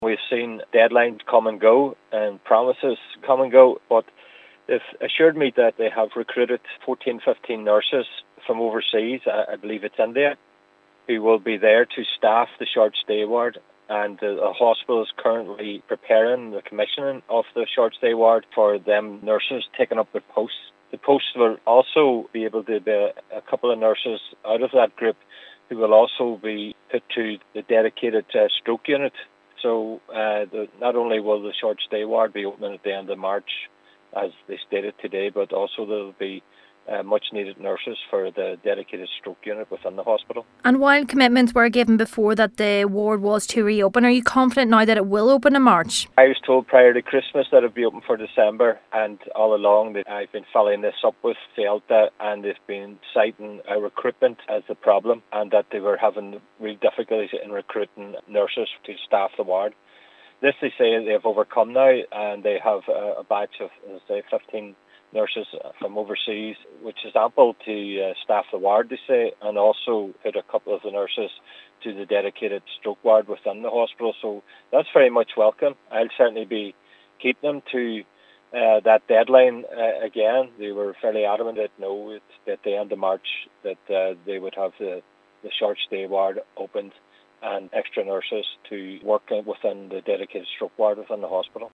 Councillor McMonagle says he is confident this most recent deadline can be met: